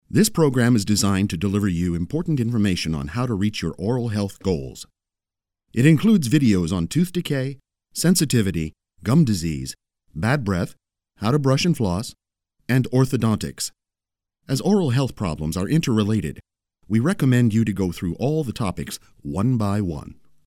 Voice of reason, warm, real, clear friendly, articulate, clear, versatile, and confident. Paternal, warm, conversational, versatile, and confident... and professional. Home studio & phone patch for direction via Skype.
Sprechprobe: eLearning (Muttersprache):